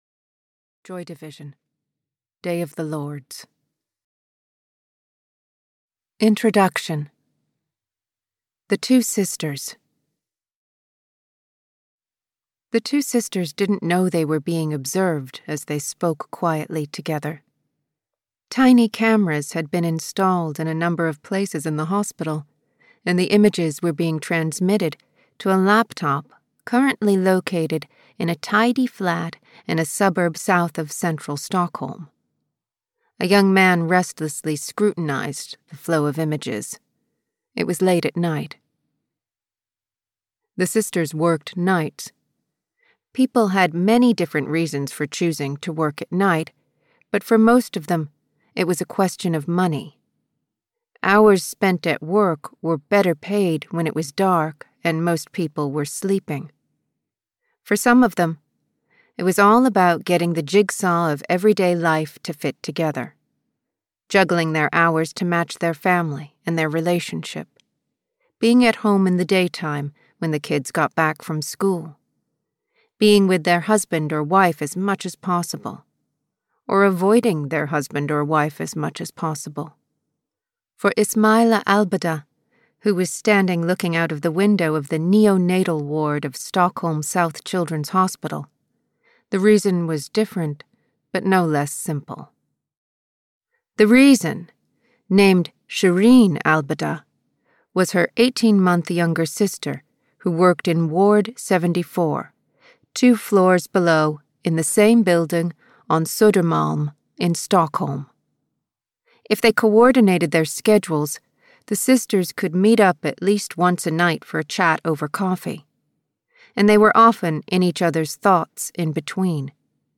Stockholm South: Siege (EN) audiokniha
Ukázka z knihy